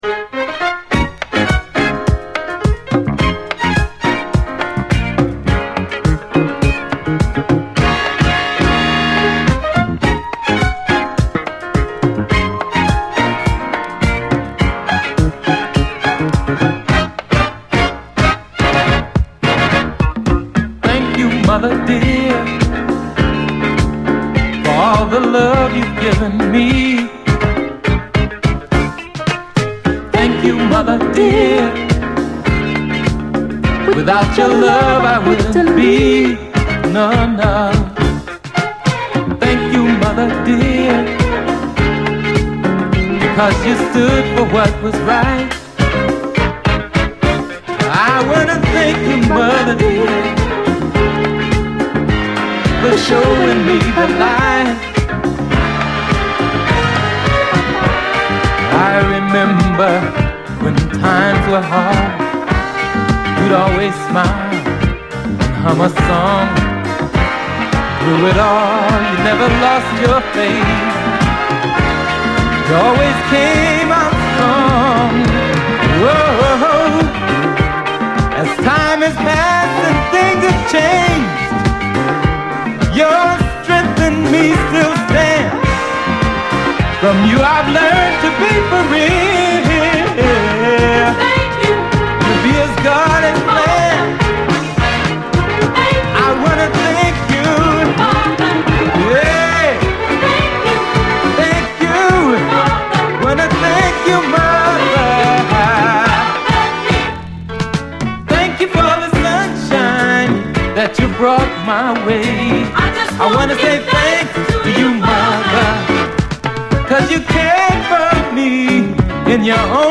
SOUL, 70's～ SOUL, DISCO
質の高い超絶メロウ・グルーヴ・トラックでカッコいい